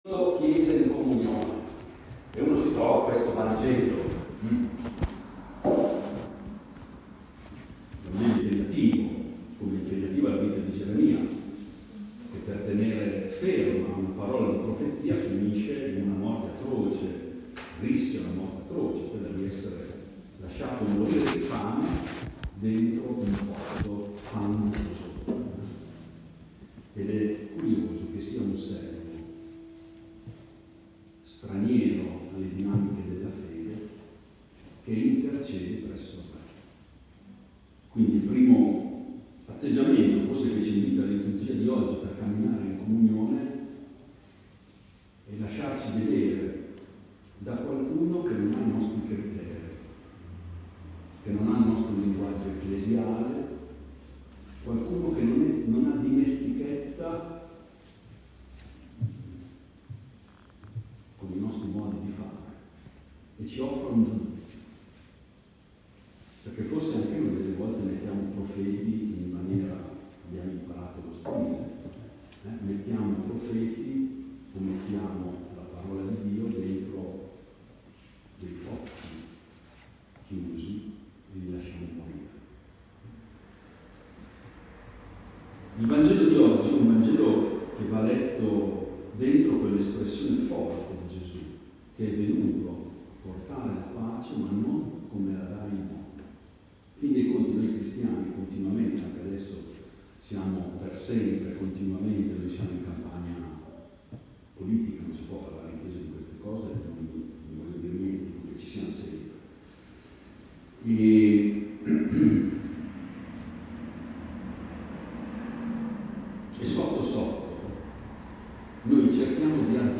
Il 13 agosto, l'appuntamento per il 13 del mese si è tenuto alla Barcaccia.